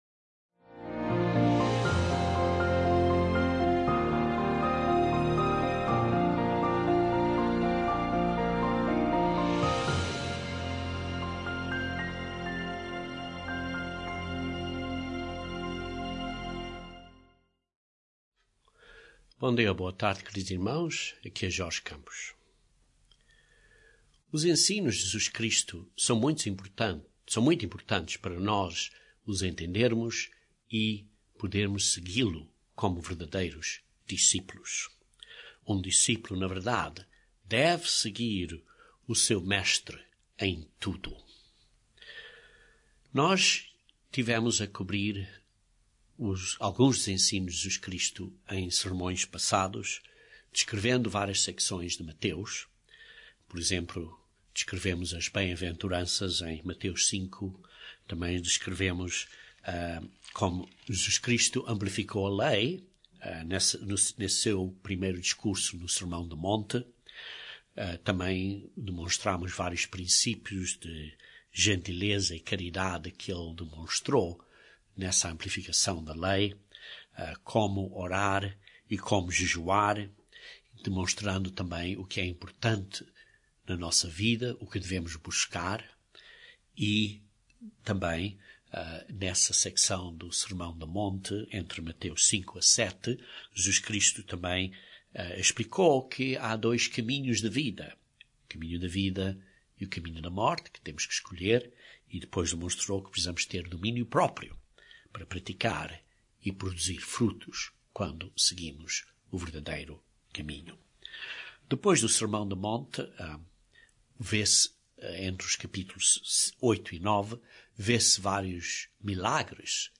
Este sermão explica para quem. Jesus disse que os discípulos precisam de seguir o Mestre em tudo. Este sermão descreve as consequências nesta vida e o futuro galardão.